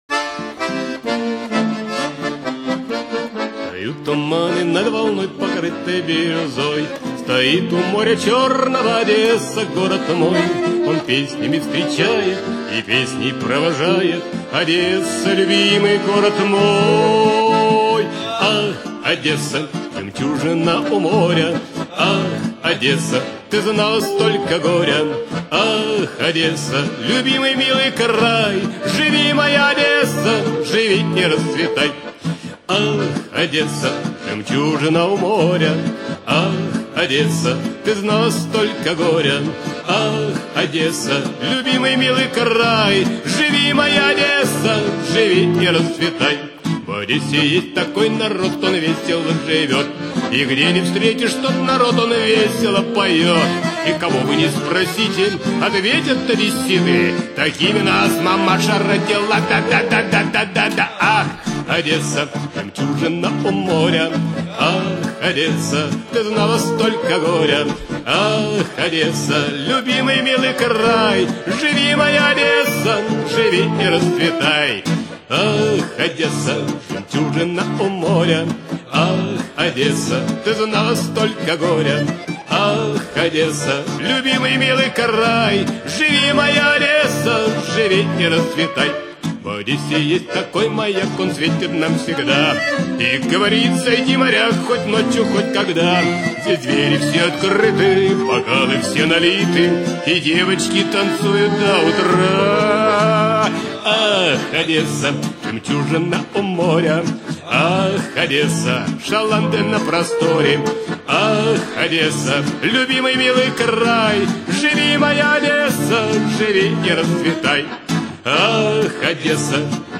Архив ресторанной музыки